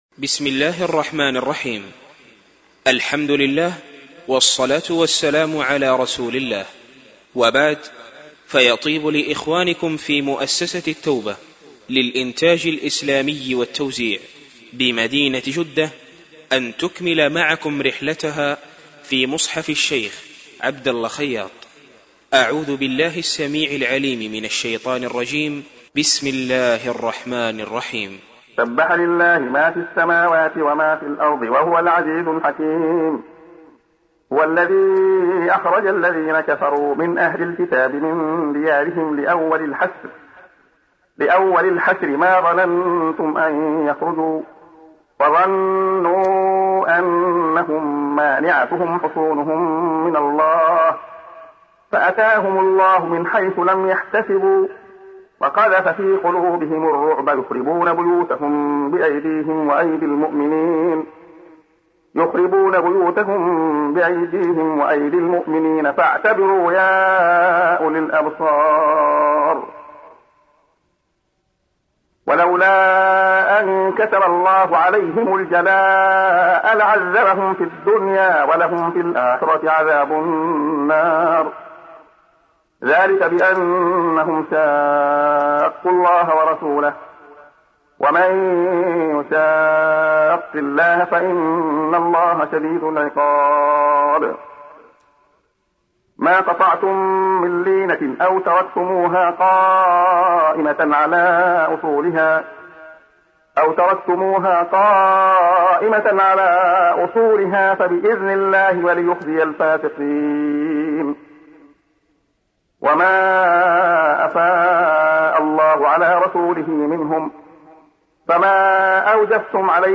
تحميل : 59. سورة الحشر / القارئ عبد الله خياط / القرآن الكريم / موقع يا حسين